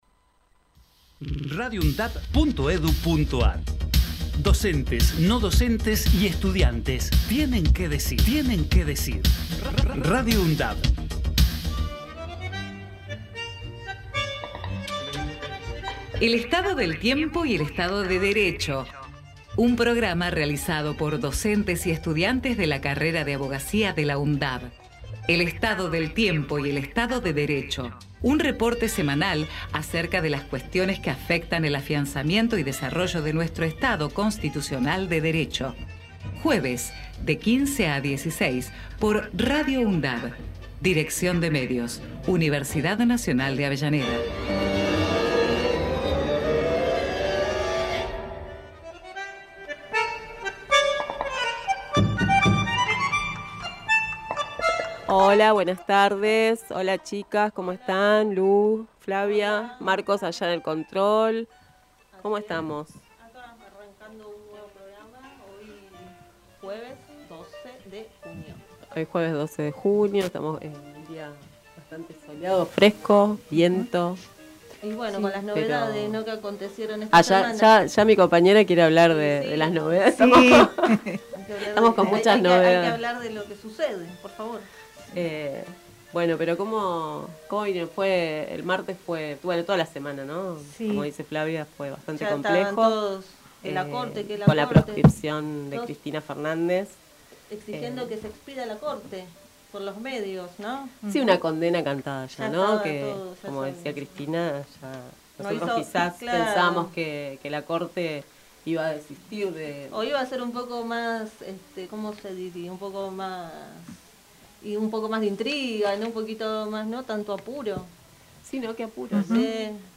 El Estado del Tiempo y el Estado de Derecho Texto de la nota: El Estado del Tiempo y el Estado de Derecho es un programa realizado por estudiantes y docentes de la carrera de Abogacía de la Universidad Nacional de Avellaneda, fue emitido por Radio UNDAV desde el año 2016 todos los jueves de 15 a 16hs.